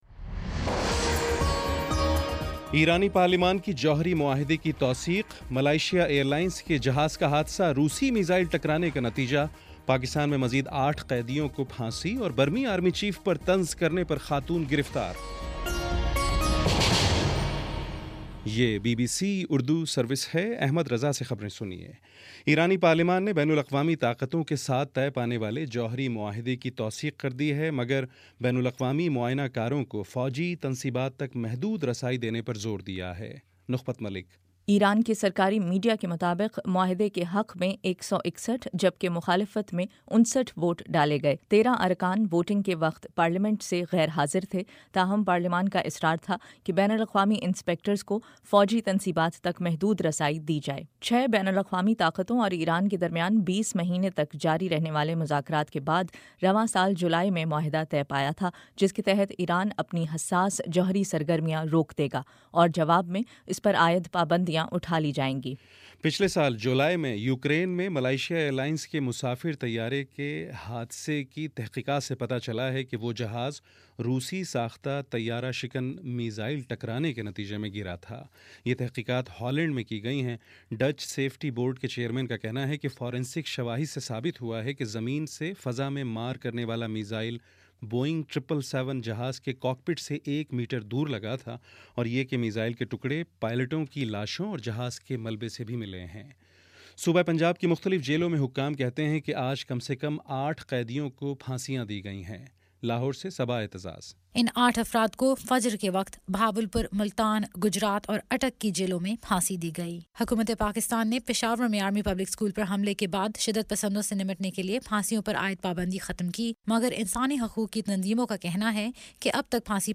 اکتوبر 13 : شام چھ بجے کا نیوز بُلیٹن